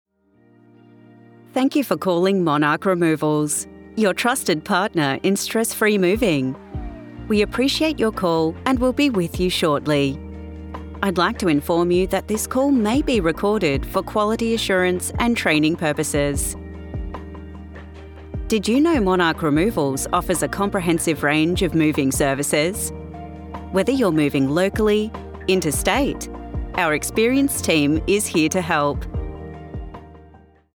English (Australia)
Natural, Versatile, Friendly, Distinctive, Commercial
Telephony